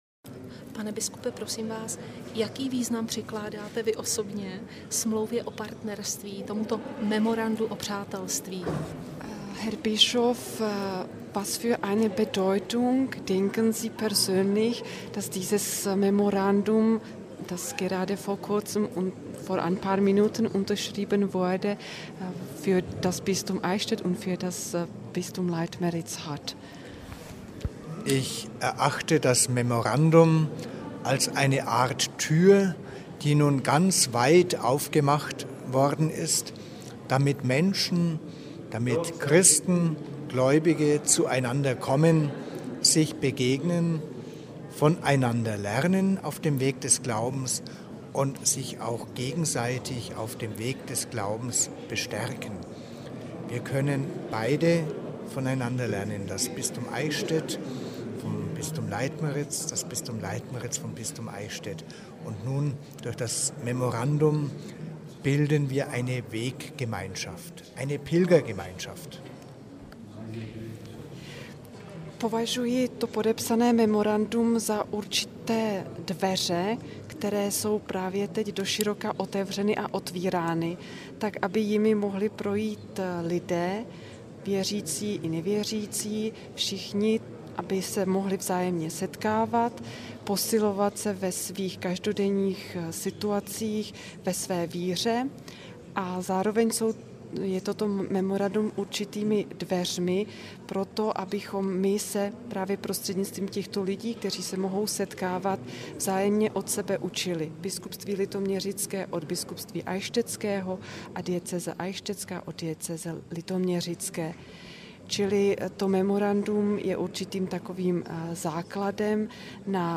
Rozhovor, Mons. Gregor Maria Hanke OSB.mp3